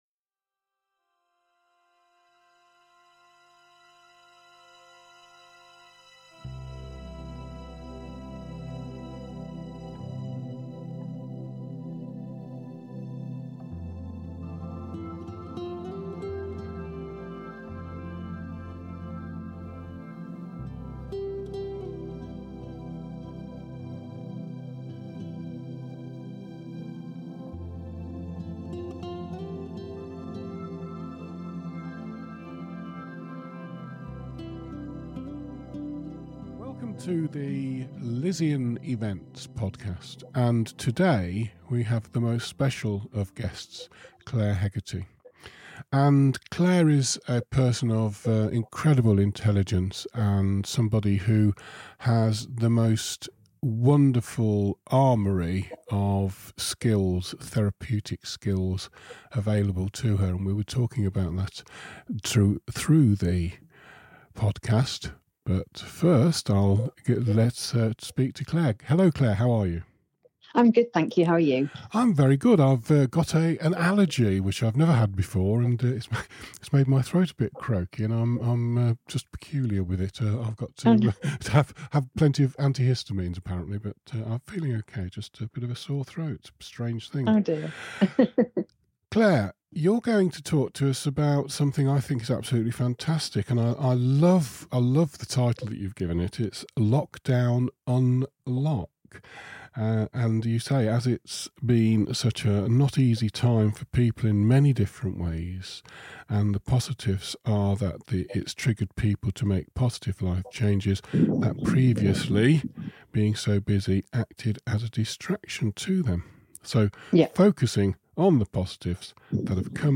Content of the Interview
The recordings are not overly edited, and we like to keep the content as authentic as possible.